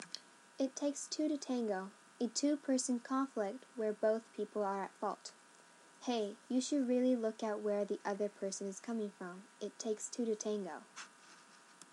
（踊りやスポーツなど、2人組で行う 何かの練習のシチュエーショ ンで） 英語ネイティブによる発音は以下をクリックしてください。